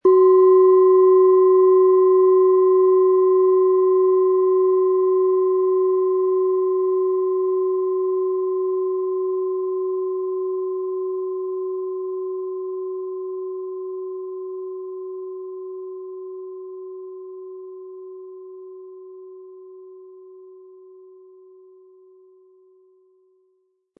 Planetenton
Im Sound-Player - Jetzt reinhören können Sie den Original-Ton genau dieser Schale anhören.
Durch die traditionsreiche Herstellung hat die Schale stattdessen diesen einmaligen Ton und das besondere, bewegende Schwingen der traditionellen Handarbeit.
SchalenformBihar
MaterialBronze